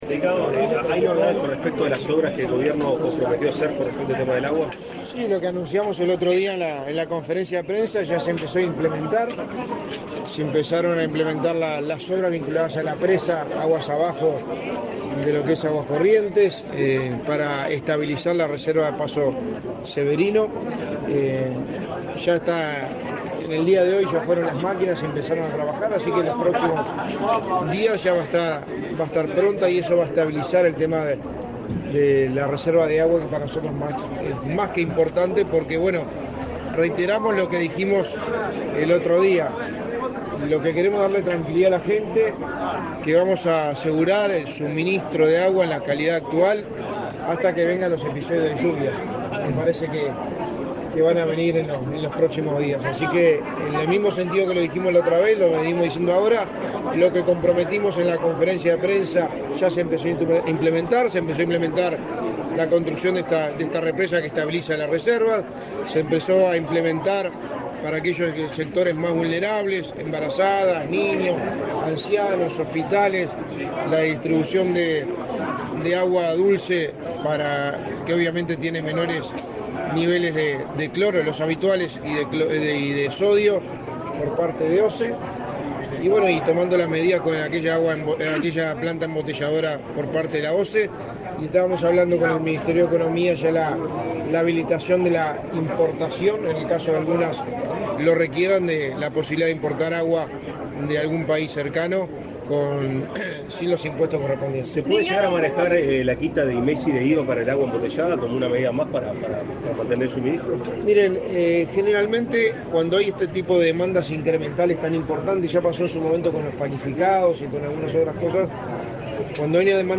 Declaraciones del secretario de Presidencia de la República, Álvaro Delgado
Declaraciones del secretario de Presidencia de la República, Álvaro Delgado 19/05/2023 Compartir Facebook X Copiar enlace WhatsApp LinkedIn Tras participar de la inauguración del Instituto de Medicina Altamente Especializada Cardiológica en el hospital de Tacuarembó, este 19 de mayo, el secretario de la Presidencia, Álvaro Delgado, realizó declaraciones a la prensa.